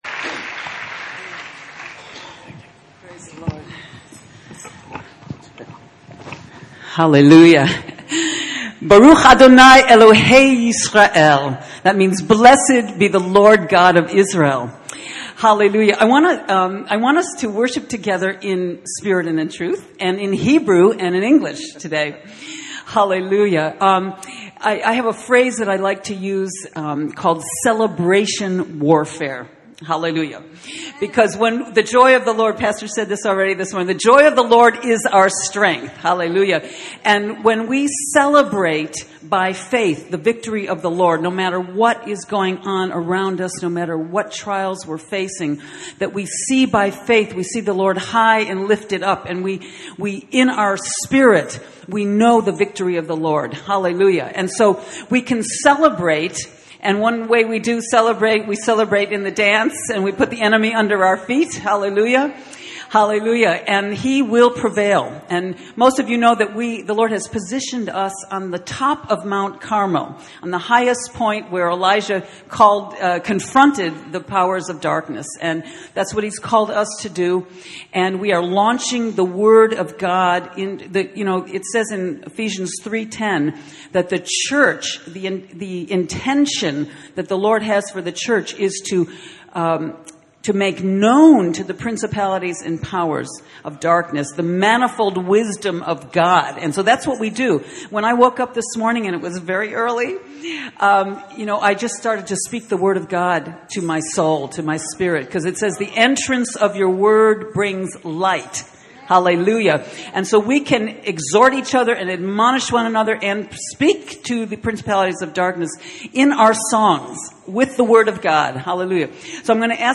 In this sermon, the speaker emphasizes the importance of not limiting God and giving Him room to work. He encourages the audience to obey God's leading, even if it goes against their understanding or seems difficult.